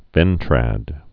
(vĕntrăd)